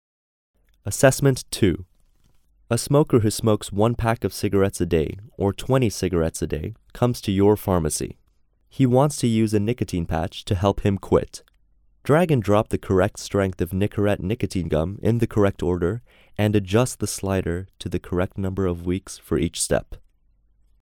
Narration audio (WAV)